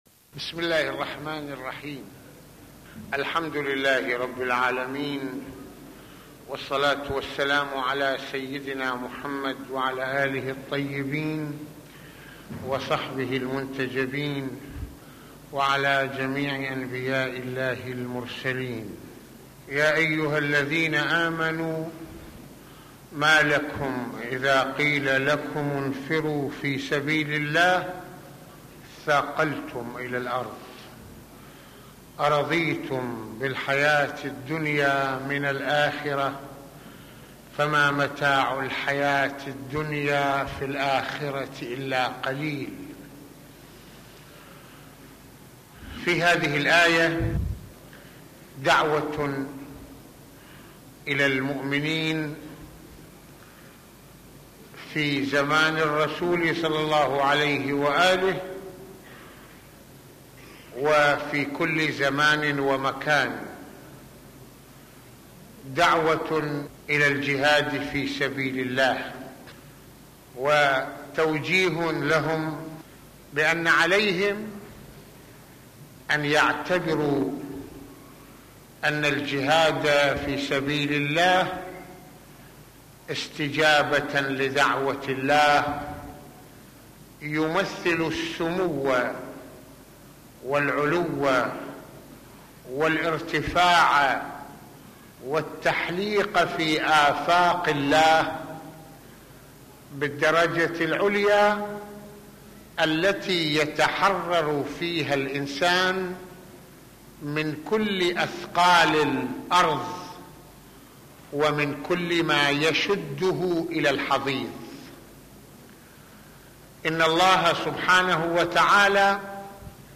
- يتحدث سماحة المرجع السيد محمد حسين فضل الله "رض" في هذه المحاضرة القرآنية عن ضرورة التمسك بنور الحق والحقيقة المتمثل بسبيل الله الذي لا تقدر أن تمحوه شبهات الجاهلين ومؤامرات المبطلين ، من هنا علينا في كل زمان الانتصارللحقيقة والحق على الباطل مهما غلت